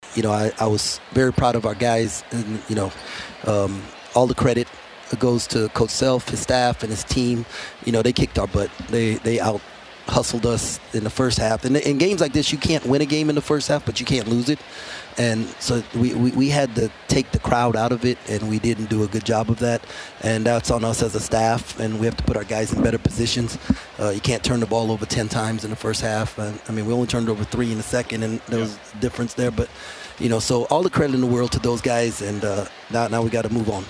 Kansas State Coach Jerome Tang gave the credit to KU.